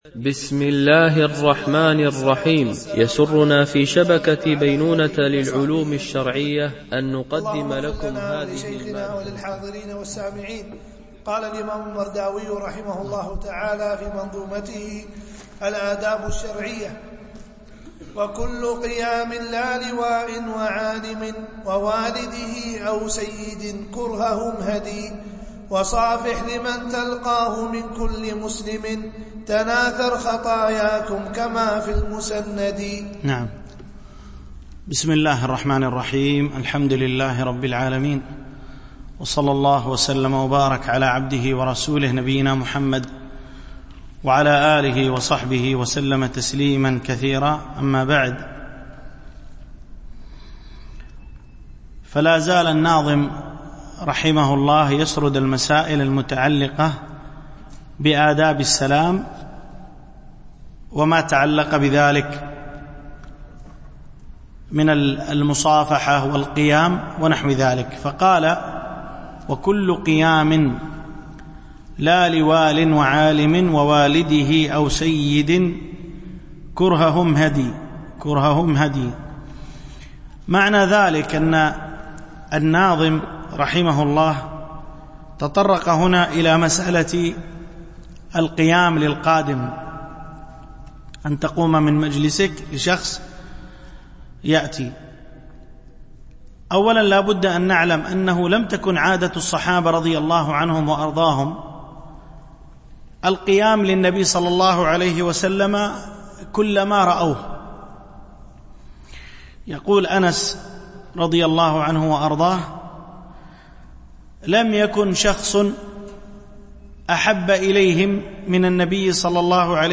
شرح منظومة الآداب الشرعية – الدرس9 ( الأبيات 103-111)
التنسيق: MP3 Mono 22kHz 32Kbps (CBR)